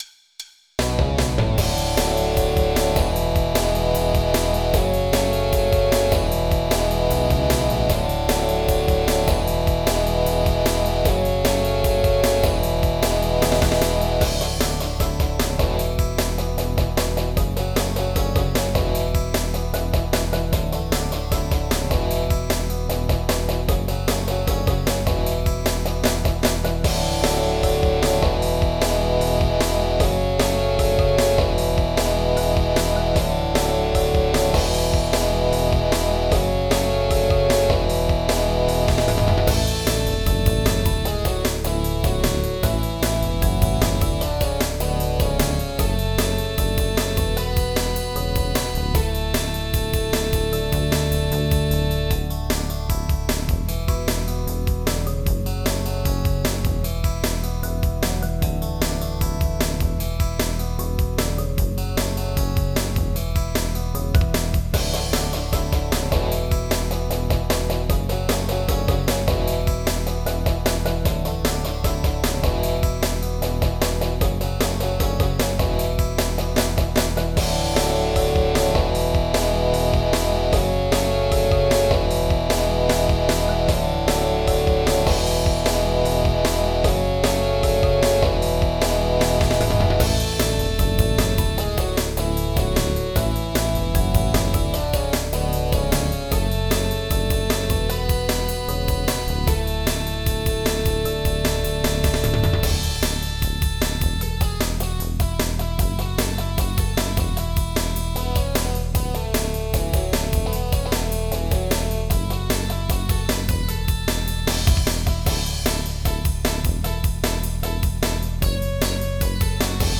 Type General MIDI